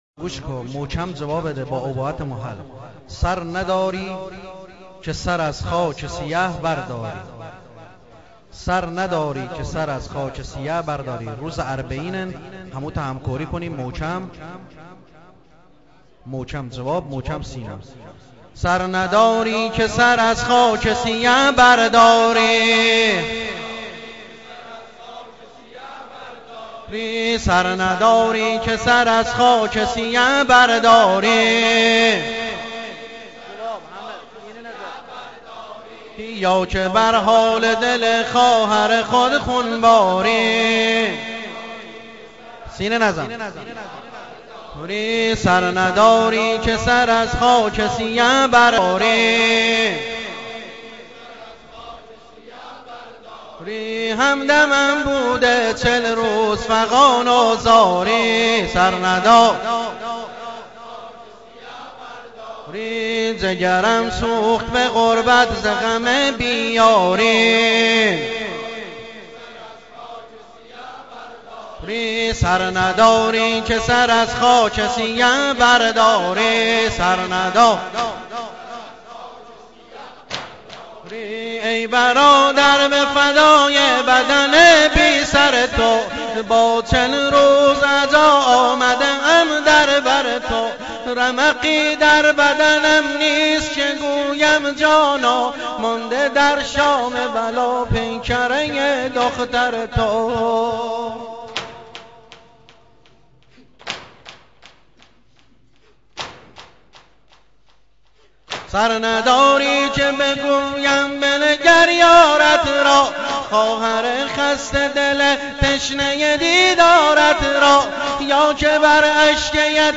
متن و سبک نوحه اربعین -( سر نداری که سر از خاک سیه برداری )